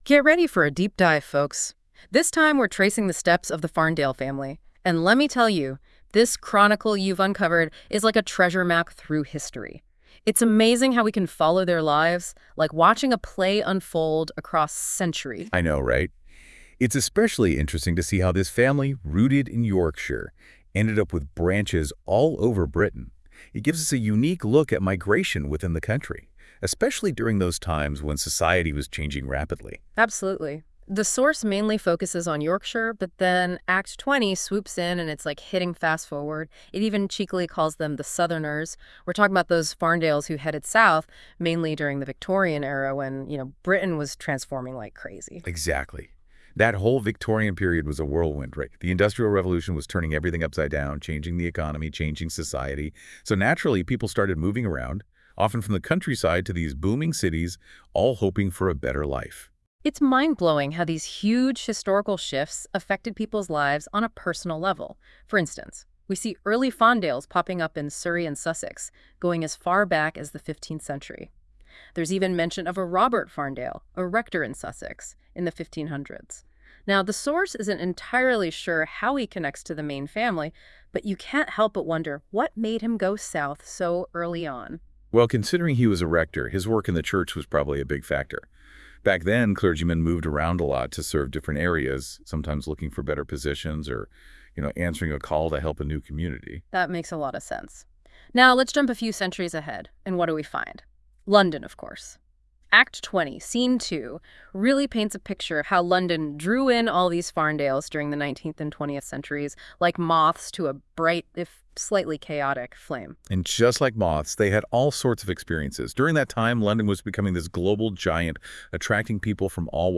Using Google’s Notebook LM, listen to an AI powered podcast summarising this page. This should only be treated as an introduction, and the AI generation sometimes gets the nuance a bit wrong.